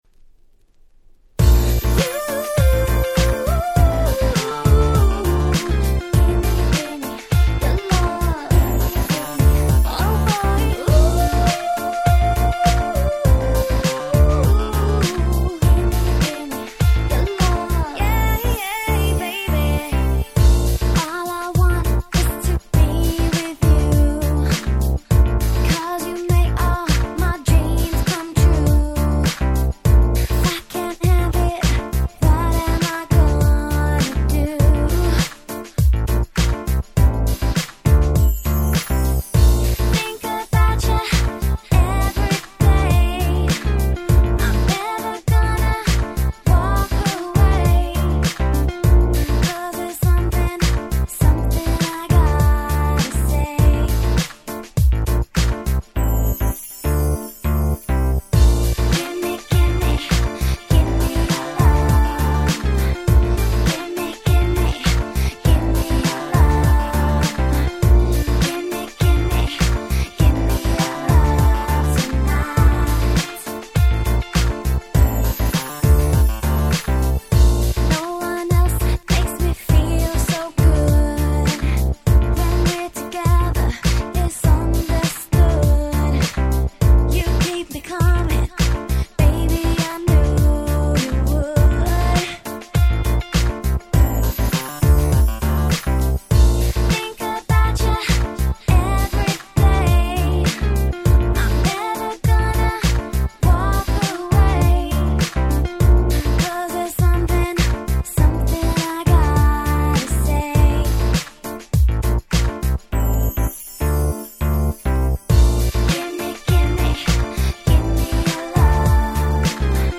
07' Nice UK R&B !!